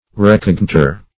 Search Result for " recognitor" : The Collaborative International Dictionary of English v.0.48: Recognitor \Re*cog"ni*tor\ (r[-e]*k[o^]g"n[i^]*t[~e]r), n. [LL.] (Law) One of a jury impaneled on an assize.